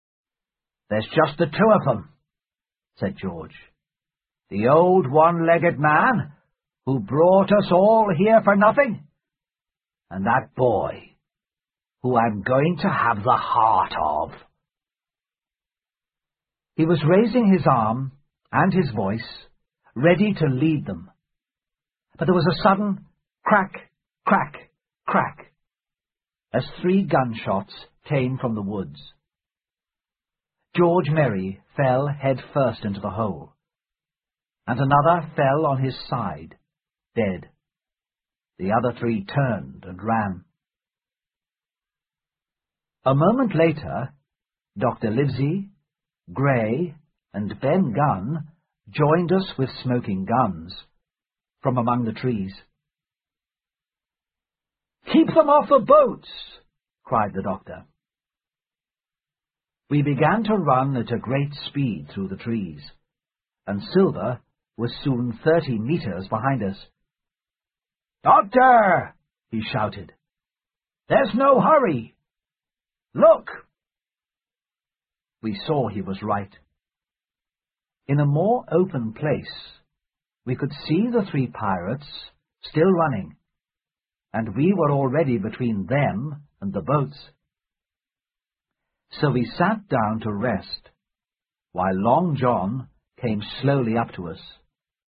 在线英语听力室《金银岛》的听力文件下载,《金银岛》中英双语有声读物附MP3下载